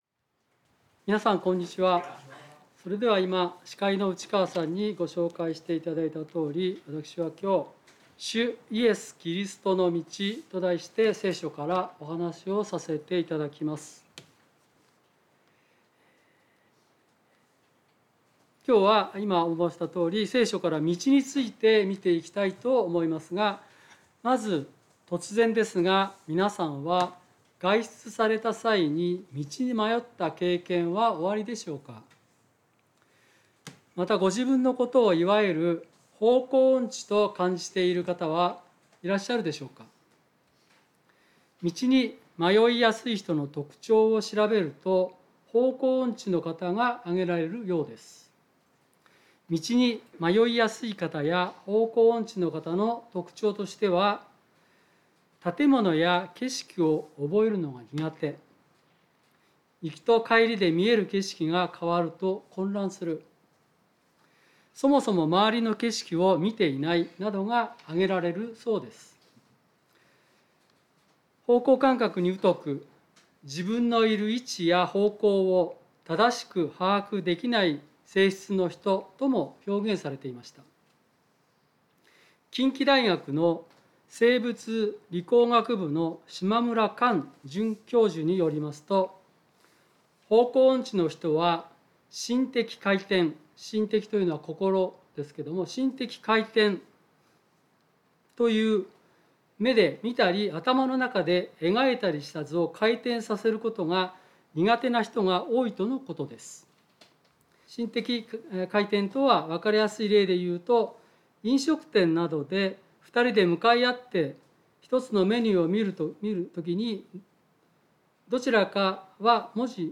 聖書メッセージ No.283